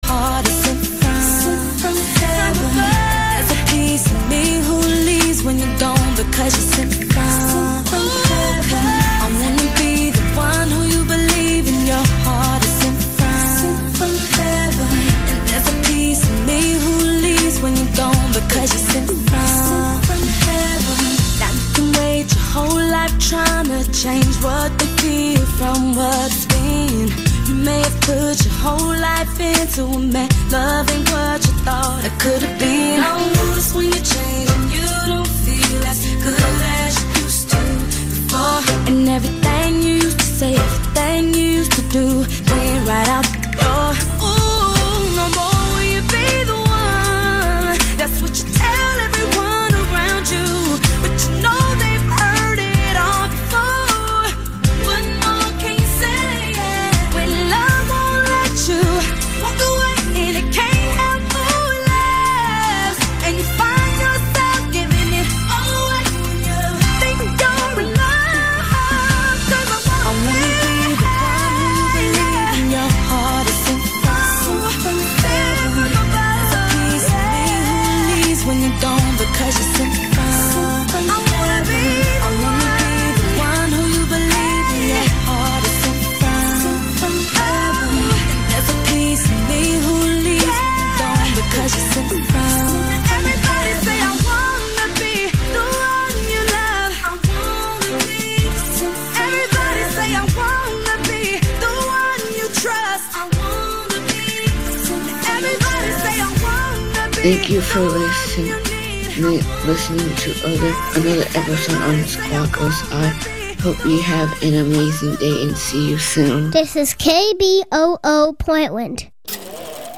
A panel broadcast reviewing the latest films and shows from the multiplex to the arthouse and beyond.